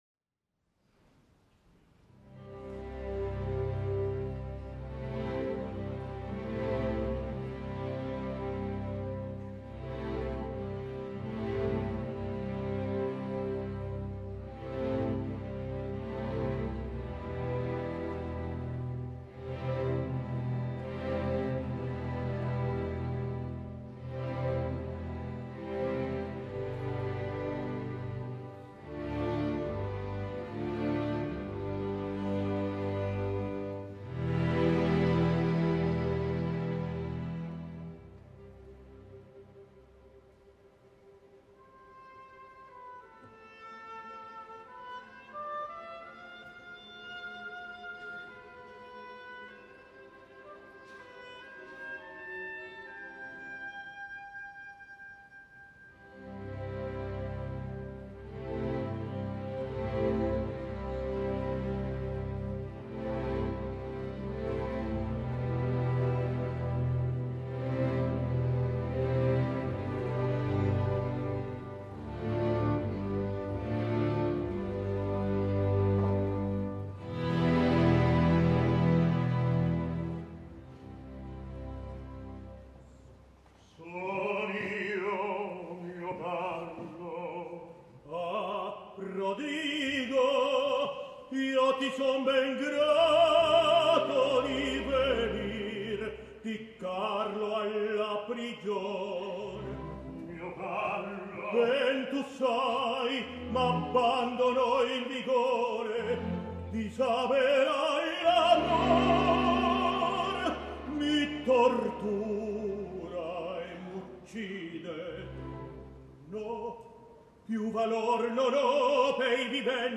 Plácido Domingo ha incorporat un nou rol a la seva carrera, el marquès de Posa del Don Carlo de Giuseppe Verdi.
El què porta a Domingo a insistir en aquesta cursa de rècord contra si mateix és un misteri inexplicable que entristeix a molts dels que varem admirar el talent d’una de les figures i personalitats artístiques més importants que ha donat la història de l’òpera i que ara, a banda de fer-nos patir (estic segur que també els succeeix a tots els que en acabar l’ària de la presó l’aplaudeixen i bravegen de manera desmesurada, pensant més en les glorioses nits de fa 30 anys que no pas en el que acaben d’escoltar) cal dir-ho clar, canta malament.